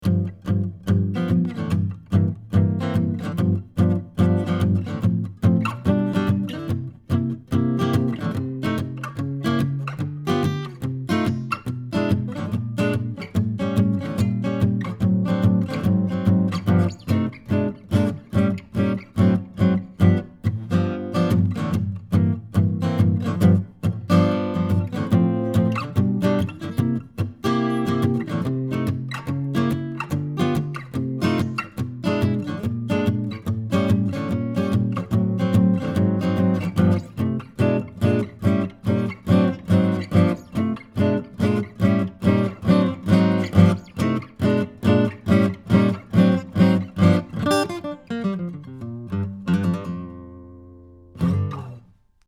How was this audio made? Here are 2 dozen quick, 1-take MP3s using this U87 in a large room -- running on battery power -- into a Sony PCM D1 flash recorder (which does not have P48 Phantom Power), with MP3s made from Logic. These tracks are just straight signal with no additional EQ, compresson or effects: Cardioid: